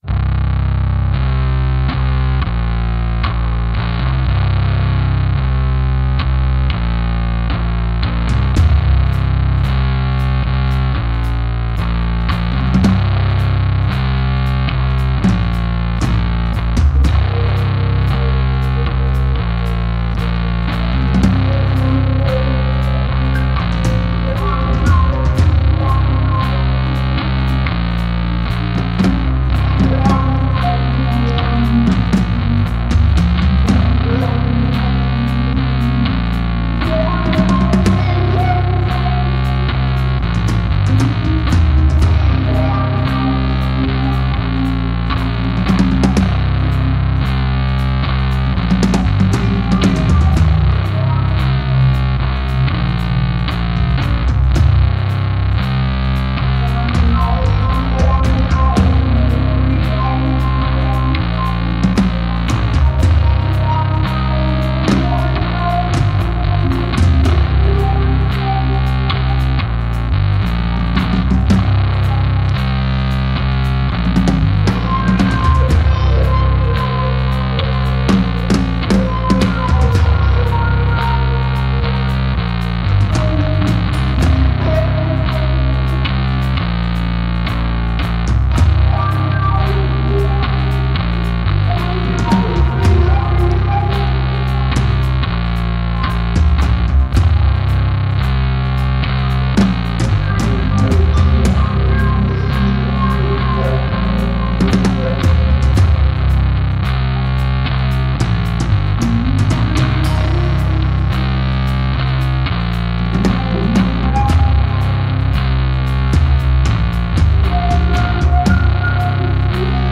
DOOM MUSIC